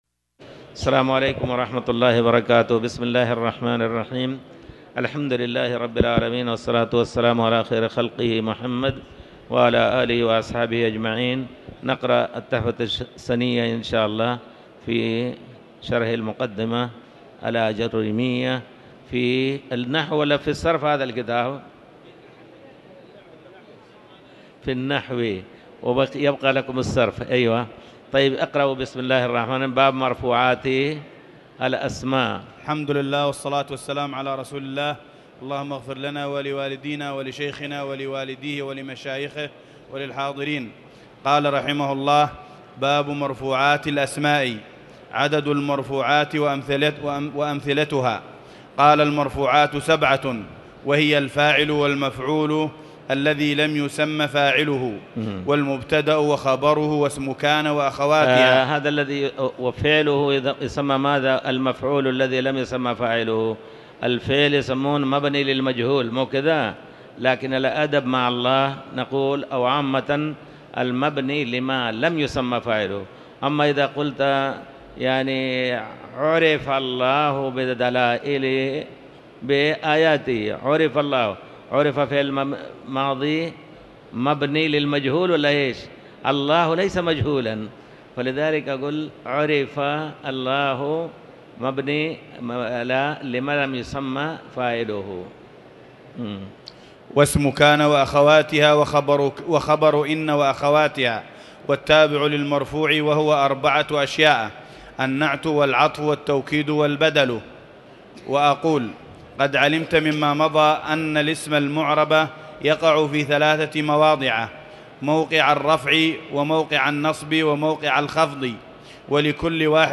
تاريخ النشر ١٣ محرم ١٤٤٠ هـ المكان: المسجد الحرام الشيخ